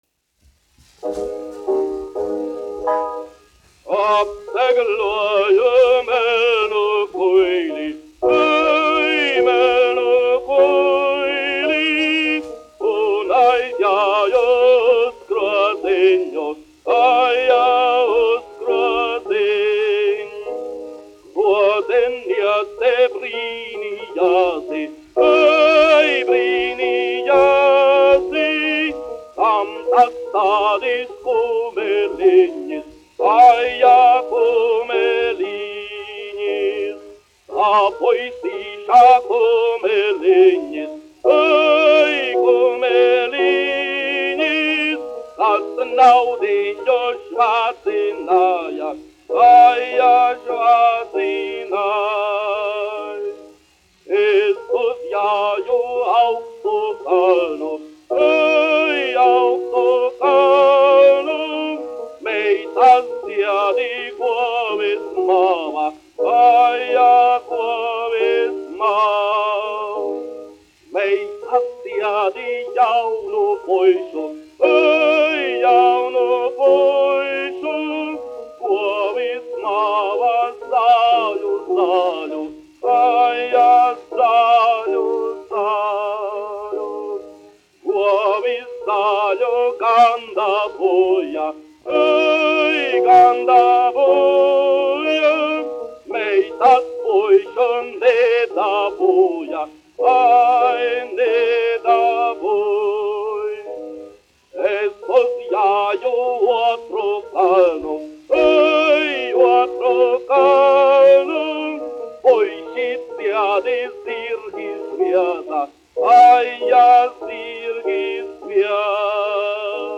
1 skpl. : analogs, 78 apgr/min, mono ; 25 cm
Latviešu tautasdziesmas
Skaņuplate